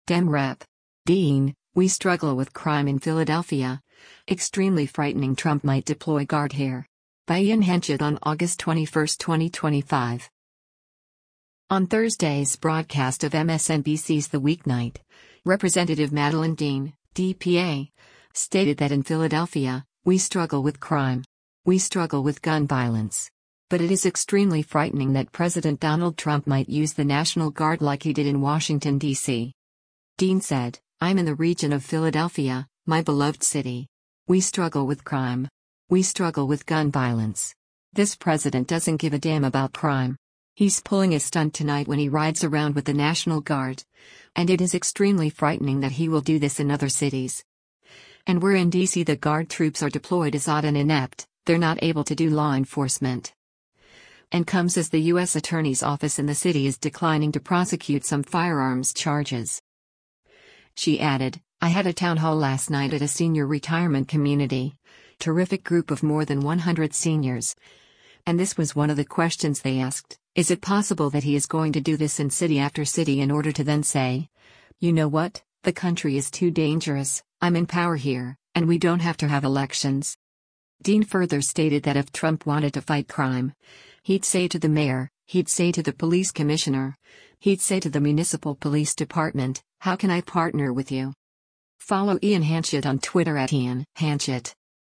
On Thursday’s broadcast of MSNBC’s “The Weeknight,” Rep. Madeleine Dean (D-PA) stated that in Philadelphia, “We struggle with crime. We struggle with gun violence.” But “it is extremely frightening” that President Donald Trump might use the National Guard like he did in Washington, D.C.